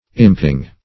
Imping \Imp"ing\ ([i^]mp"[i^]ng), n. [See Imp to graft.]